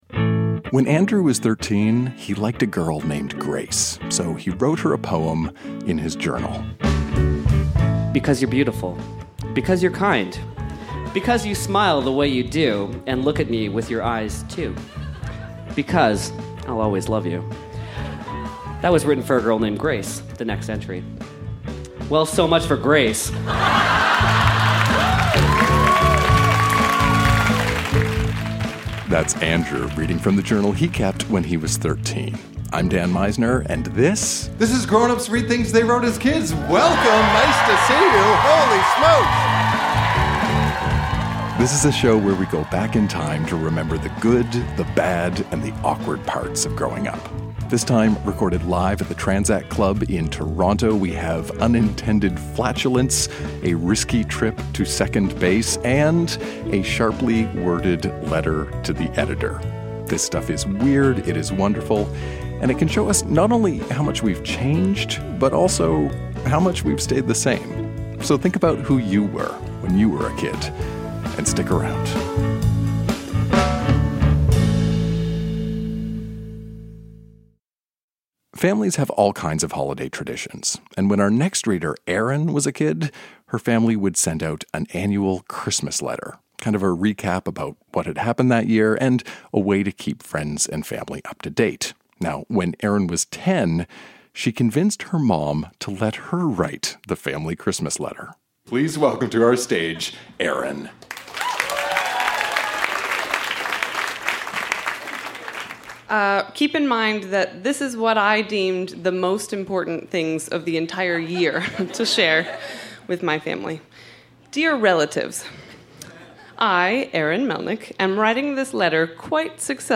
Recorded live at The Tranzac Club in Toronto, ON.